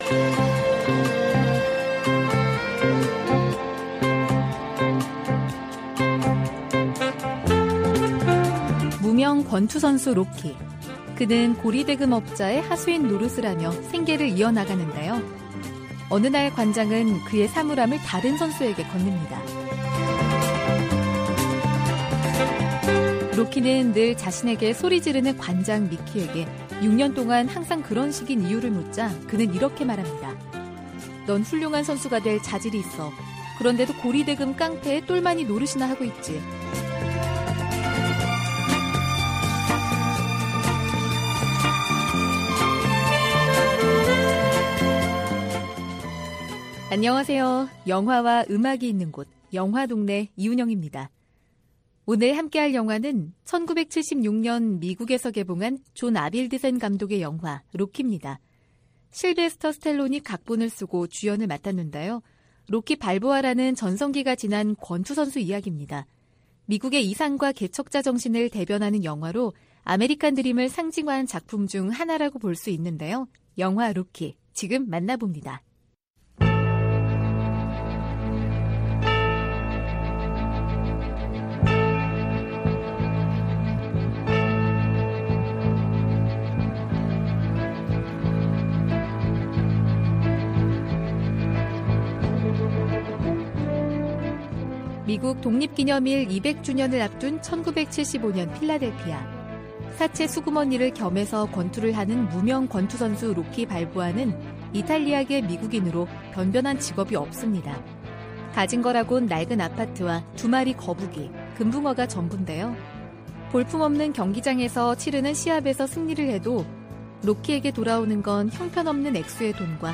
VOA 한국어 방송의 일요일 오전 프로그램 2부입니다.